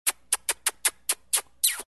Звуки поцелуя
Звук швидких поцілунків